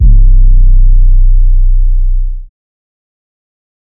Metro Poopin' 808 (C).wav